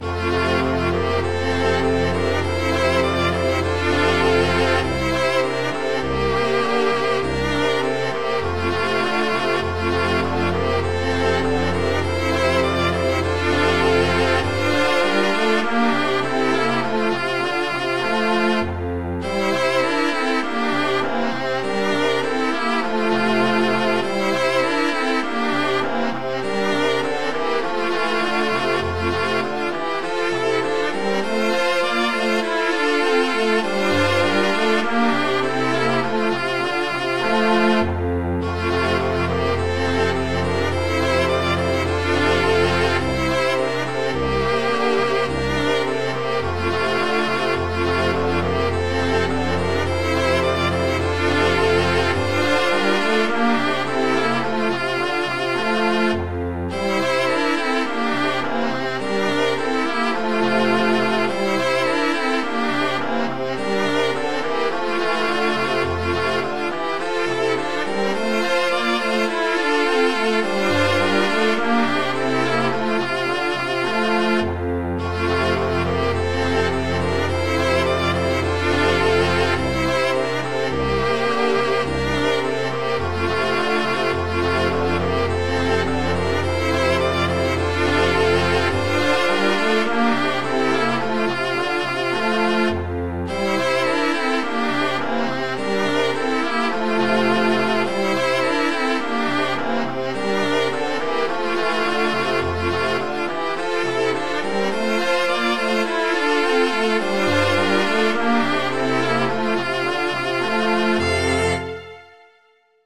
Midi File, Lyrics and Information to Long, Long Agor